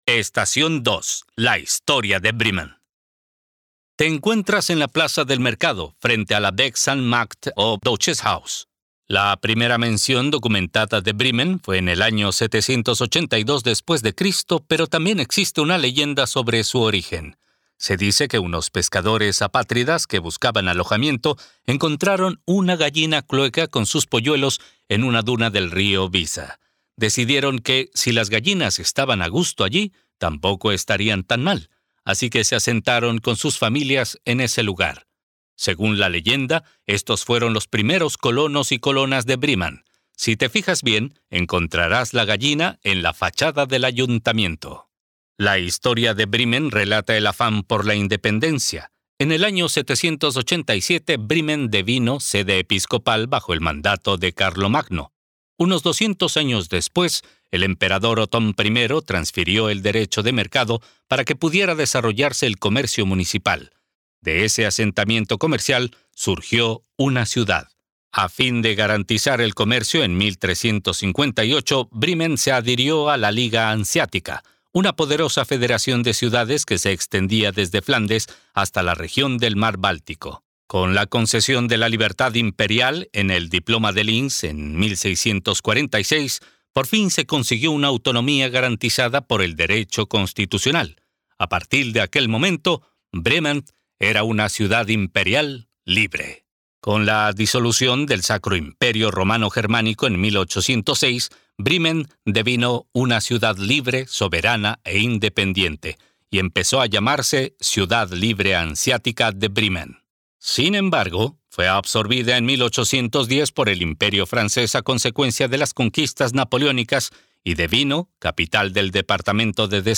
Audioguía gratuita: Un paseo por el centro histórico de Bremen